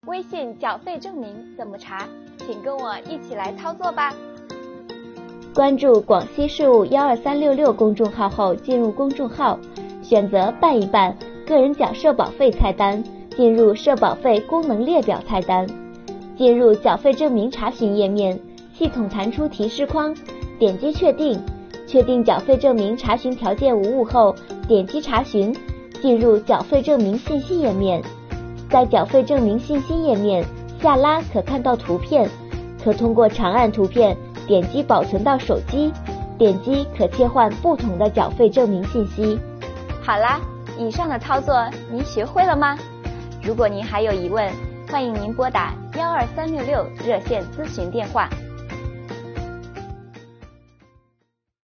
灵活就业人员的小伙伴们，现在缴社保费就请关注广西税务12366微信公众号，打开下面的视频，跟着税务小姐姐一起来轻松缴社保吧！